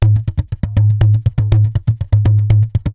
PERC 01.AI.wav